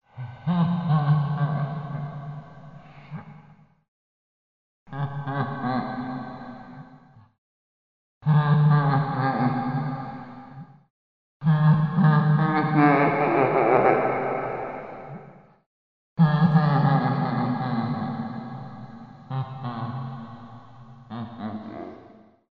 Звуки дьявола, черта
Звук сатанинской насмешки